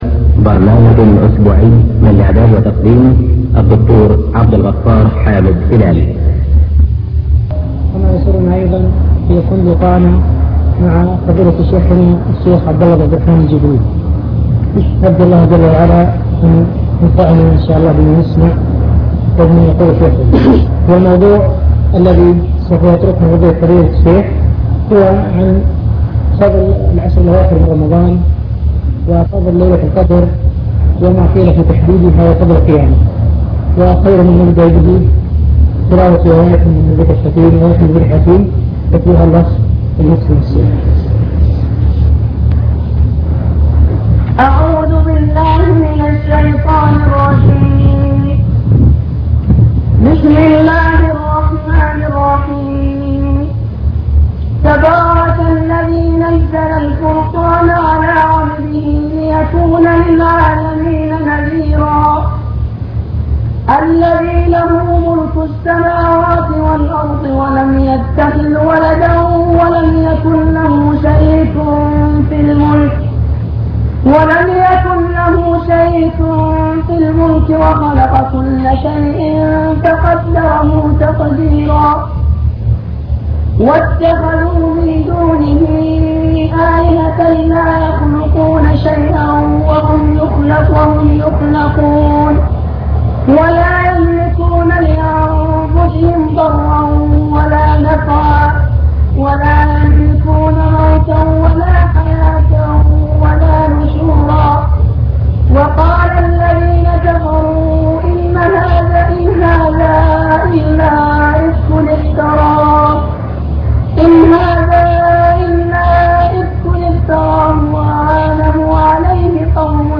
المكتبة الصوتية  تسجيلات - محاضرات ودروس  مجموعة محاضرات ودروس عن رمضان العشر الأواخر من رمضان